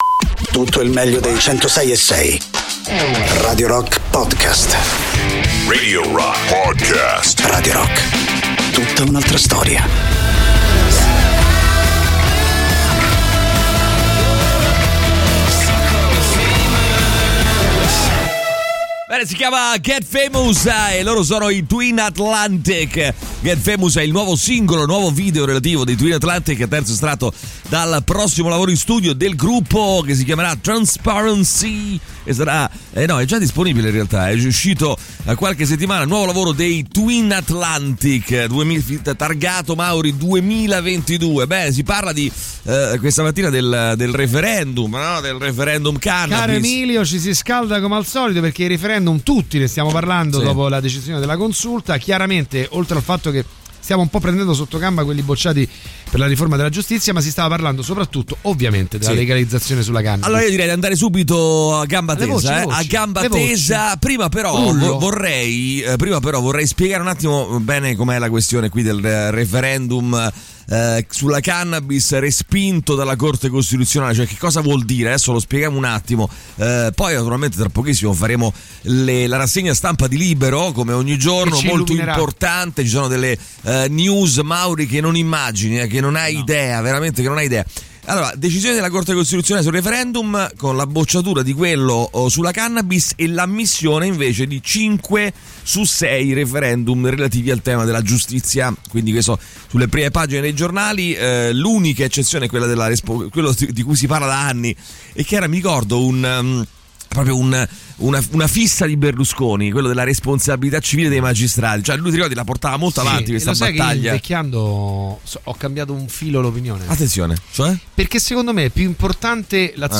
in diretta dal lunedì al venerdì dalle 6 alle 10 sui 106.6 di Radio Rock